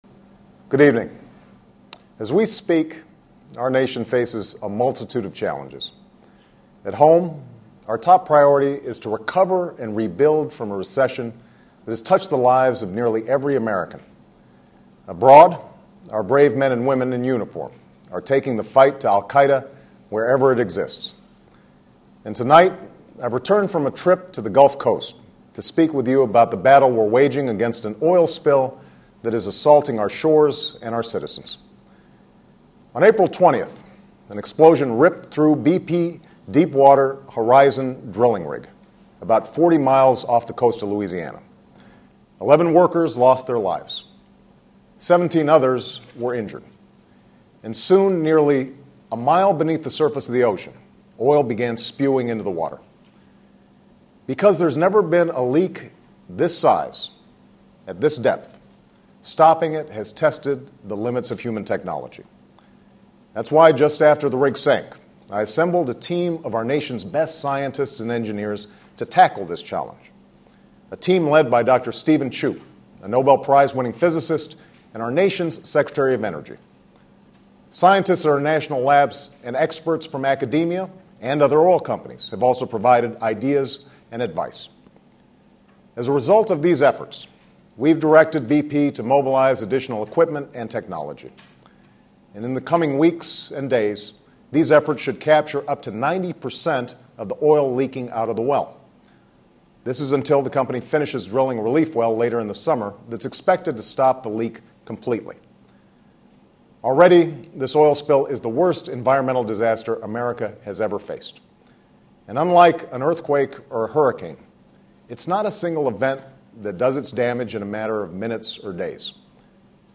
President Obama's remarks on the BP oil spill.